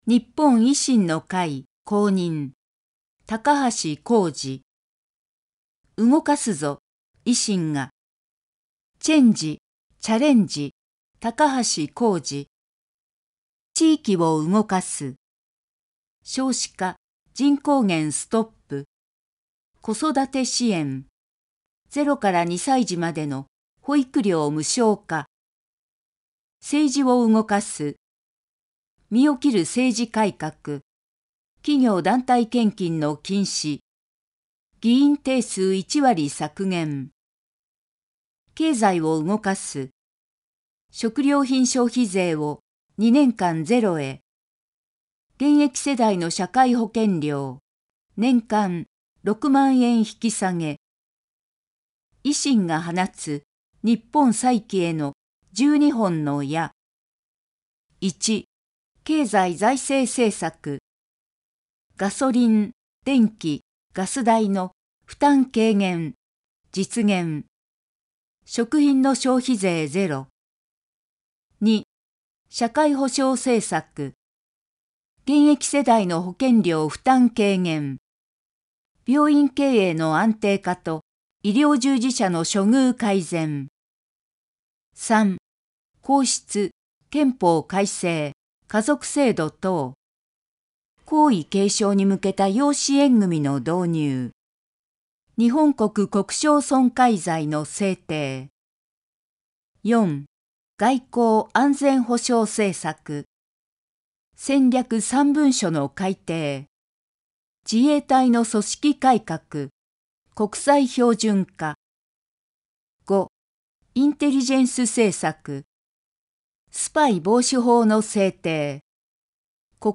衆議院議員総選挙候補者・名簿届出政党等情報（選挙公報）（音声読み上げ用）